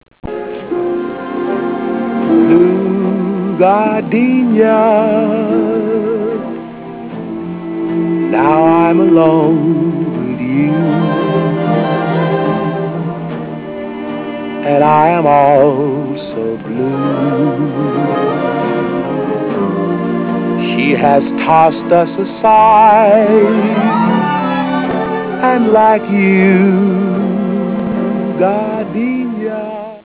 Track Music